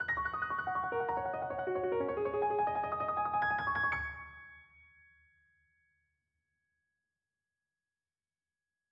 쇼팽연습곡 G♭장조, 작품 10, 5번 "흑건" 연습곡[1]은 장음계의 오음음계이다.